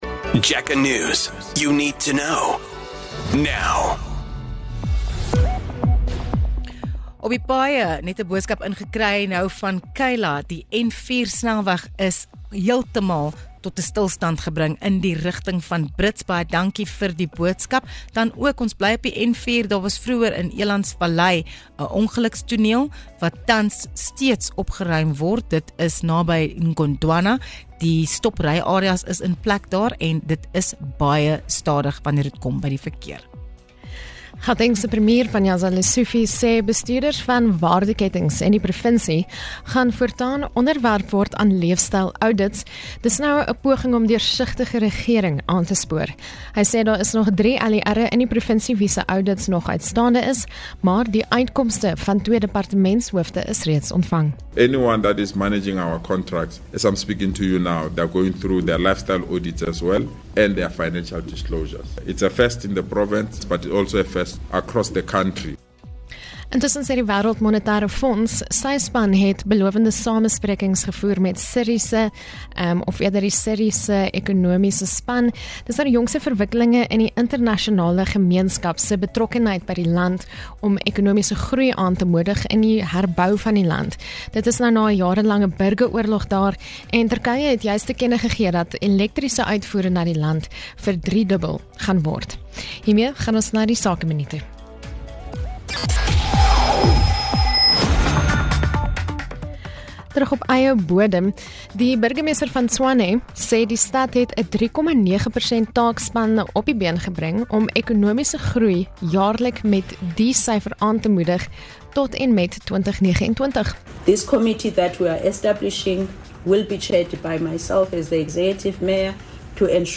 Jacaranda FM News Bulletins